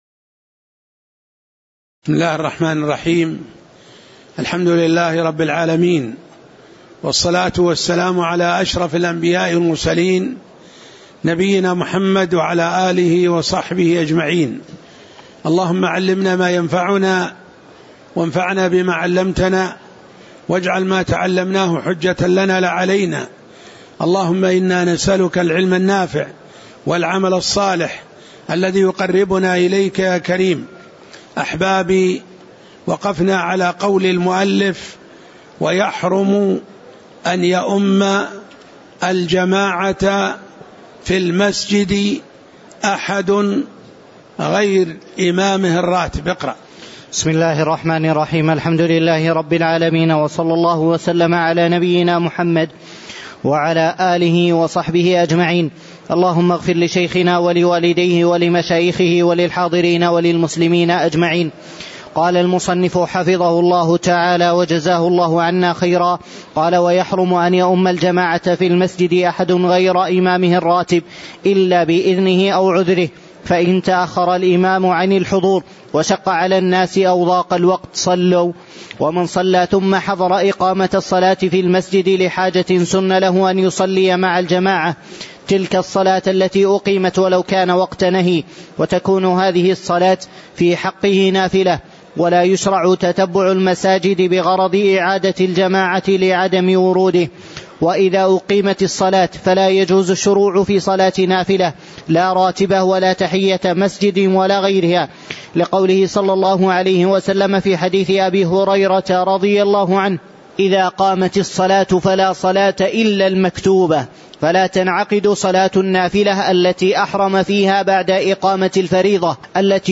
تاريخ النشر ١ جمادى الأولى ١٤٣٩ هـ المكان: المسجد النبوي الشيخ